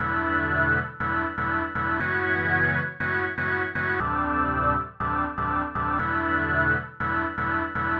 描述：一个复古的lo fi低音线，用我的卡西欧HZ600合成器制作。适合于恍惚，也许是房子或电子之类的。
Tag: 140 bpm Electronic Loops Bass Synth Loops 2.31 MB wav Key : C